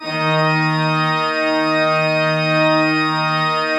Index of /90_sSampleCDs/Propeller Island - Cathedral Organ/Partition I/PED.V.WERK R